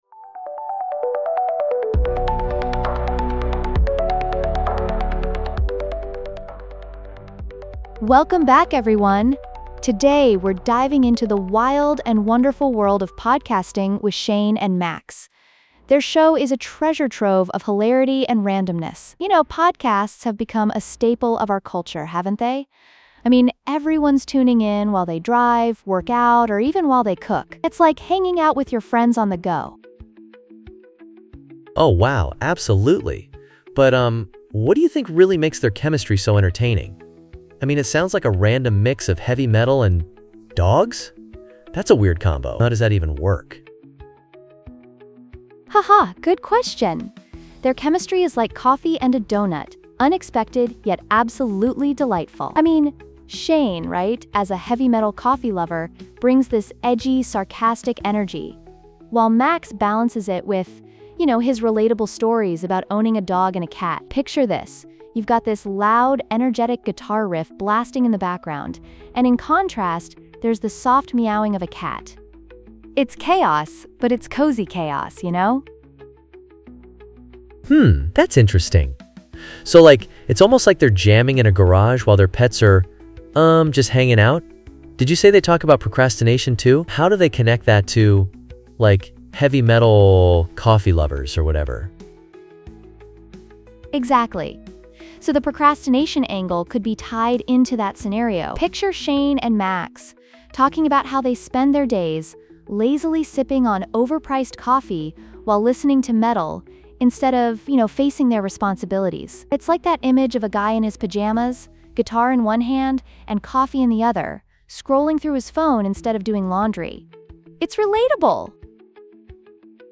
The 5 minute AI Generated Episode 5:37